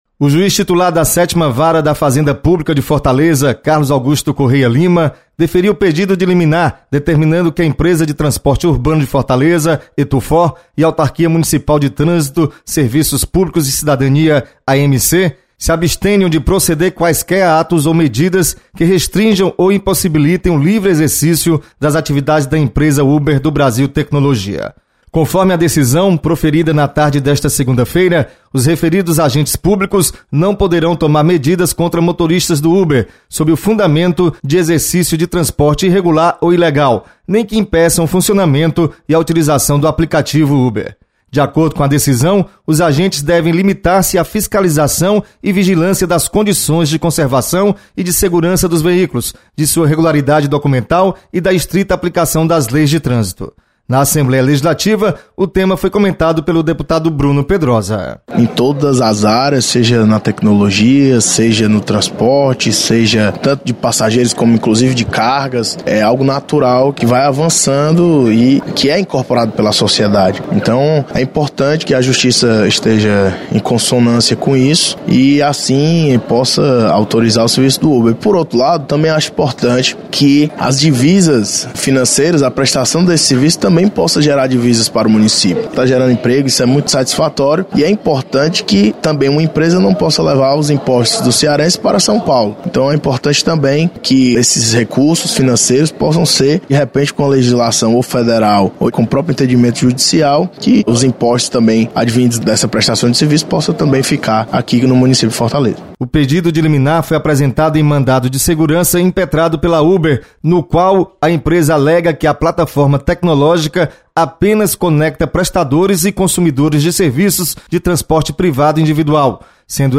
Deputado Bruno pedrosa comenta sobre decisão que beneficia o Uber.